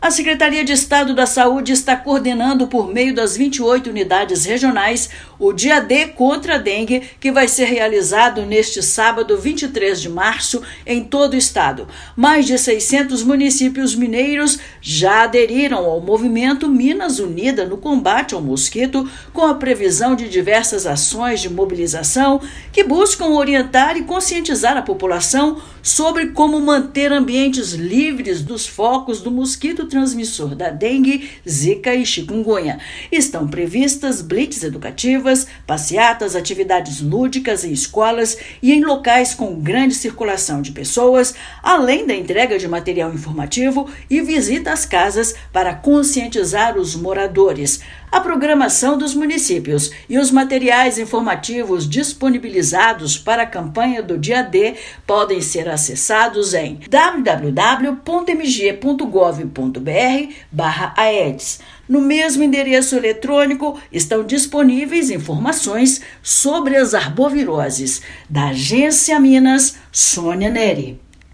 [RÁDIO] Minas Gerais promove segundo Dia D de combate às arboviroses
Mais de 600 municípios aderiram à iniciativa e vão promover ações neste sábado. Ouça matéria de rádio.